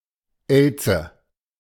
Elze (German pronunciation: [ˈɛlt͡sə]